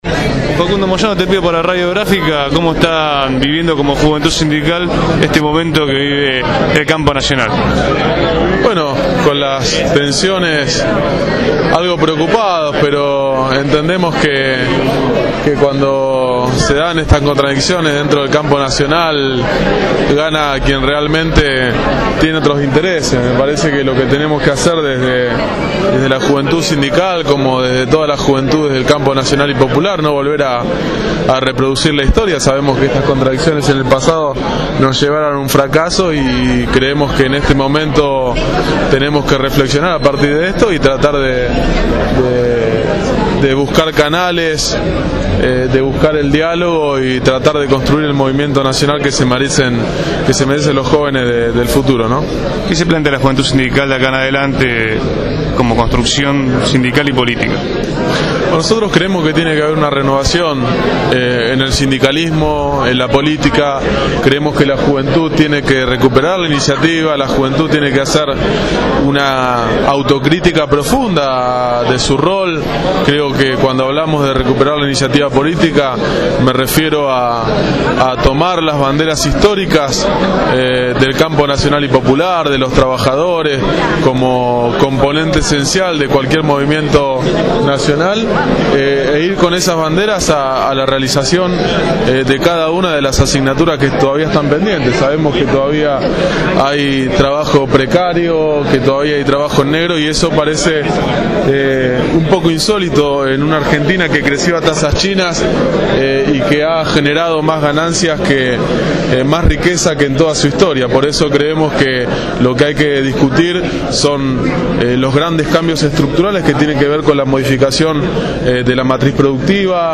Facundo Moyano, Diputado Nacional por el frente Para la Victoria participó en la presentación de la obra del historiador Norberto Galasso «La Compañera Evita»  en el ND/Ateneo y  fue entrevistado por Radio Gráfica dando una mirada sobre la realidad política y sindical que se vive en medio de la ruptura del movimiento obrero.